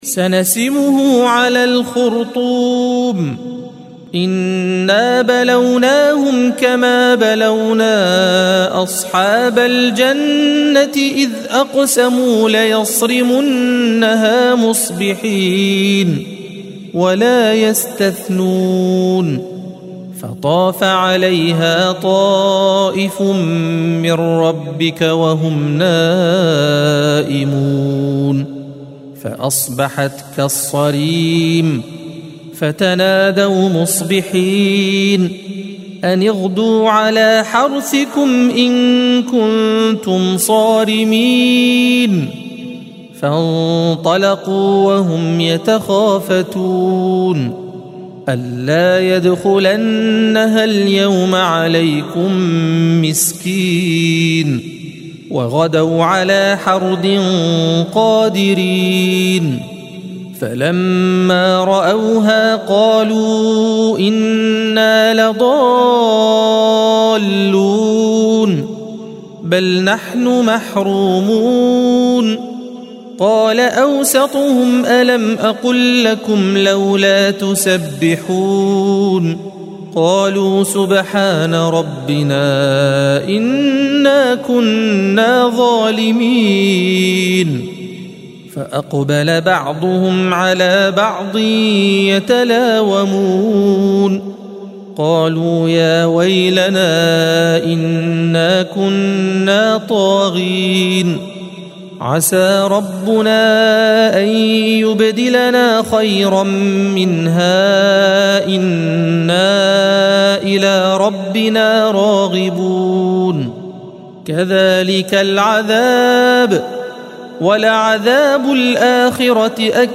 الصفحة 565 - القارئ